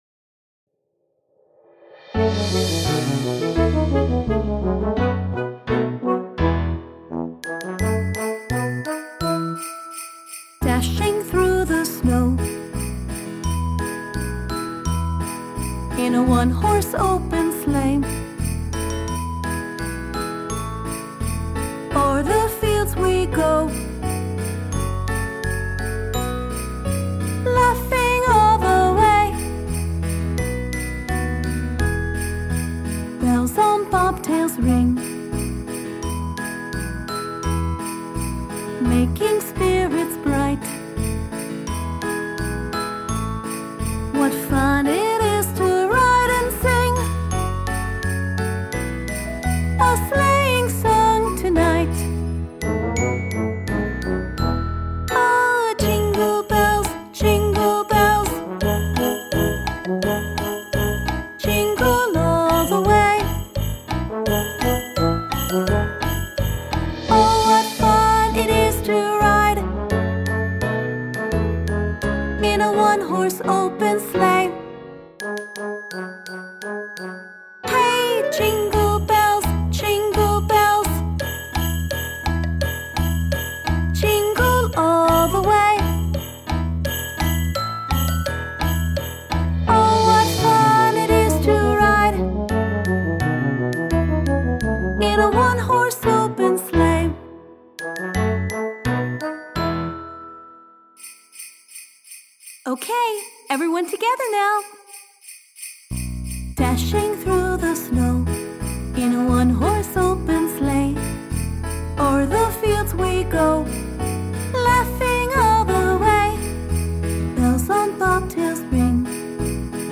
CHRISTMAS SONG, JINGLE BELLS
2 – Aquí teniu la cançó més lenta!! Jingle Bells (Learn & Sing)